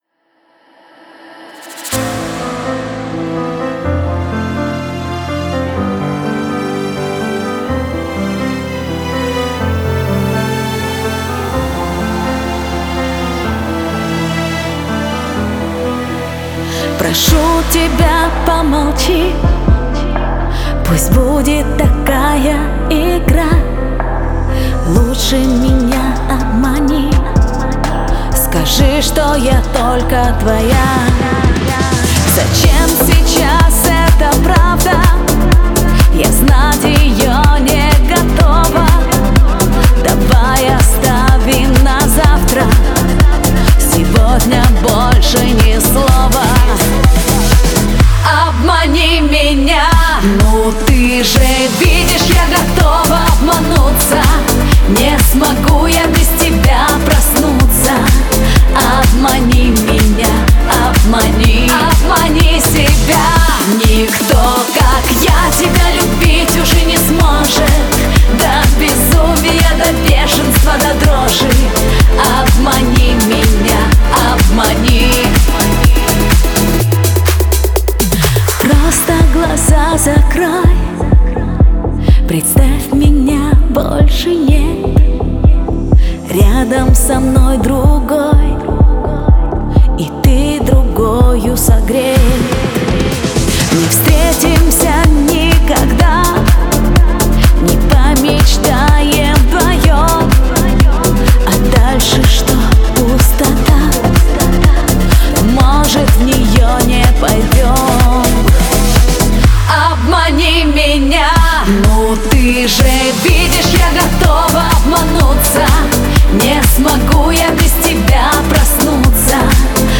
яркая поп-песня